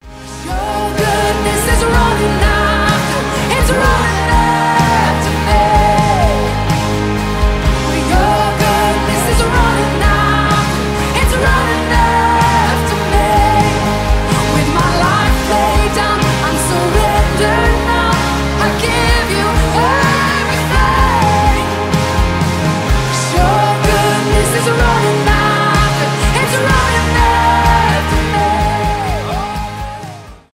христианские , зарубежные , поп , live